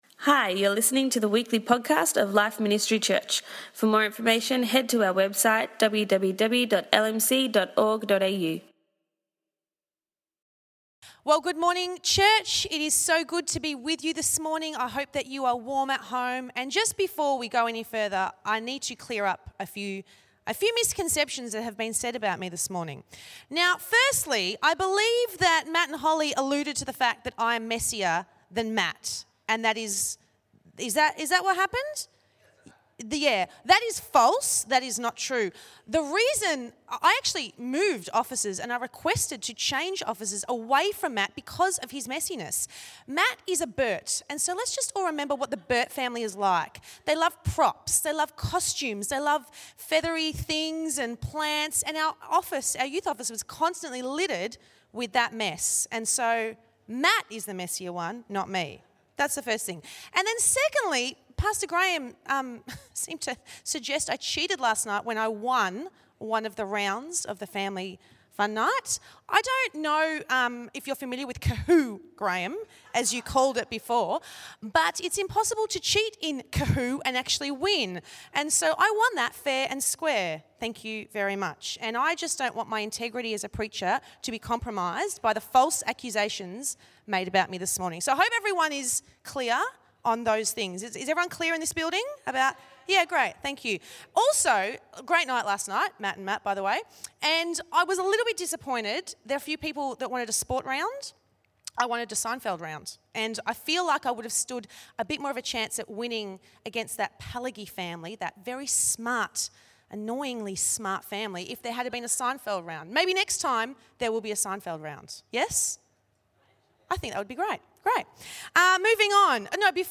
In this week's final message for our Conditions for Growth series